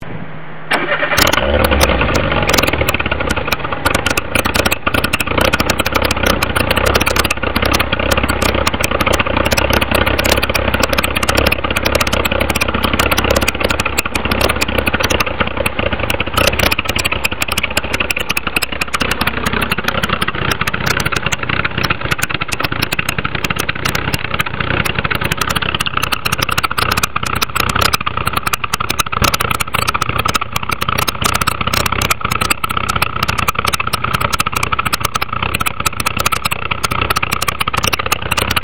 Moteur
echappement
echappement.mp3